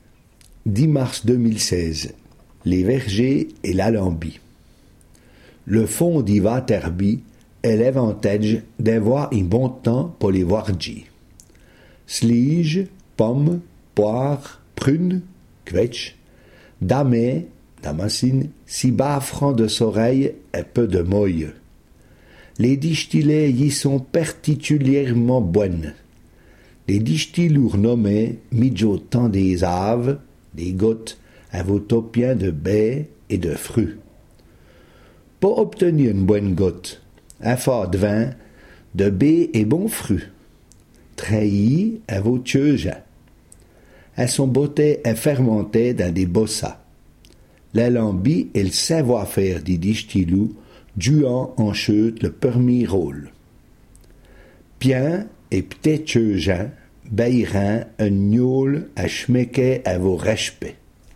Sommaire Ecouter le r�sum� en patois Panneau complet, consulter ou Situation ; coordonn�es (...)